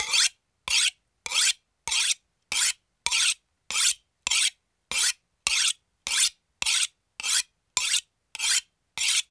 Kitchen Knife Sharpening Sound
household